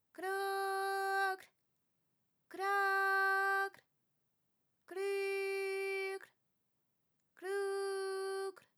ALYS-DB-002-FRA - First publicly heard French UTAU vocal library of ALYS